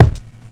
kits/RZA/Kicks/WTC_kYk (27).wav at main